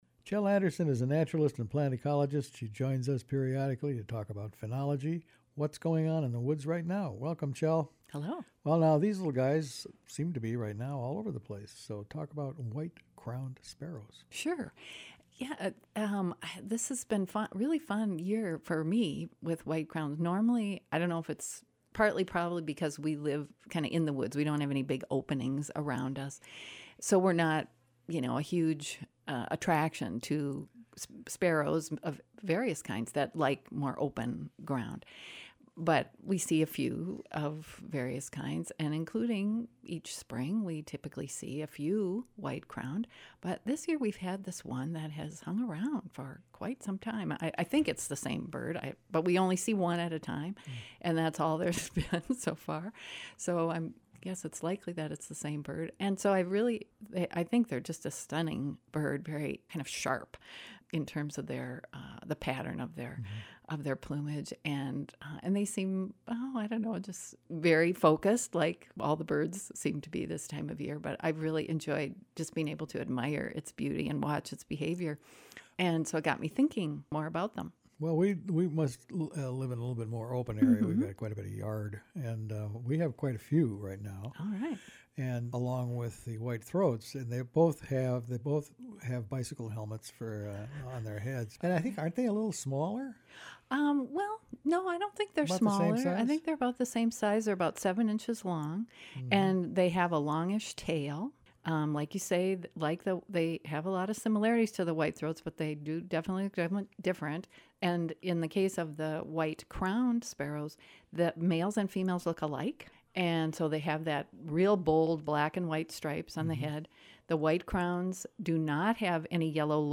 talks with naturalist